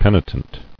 [pen·i·tent]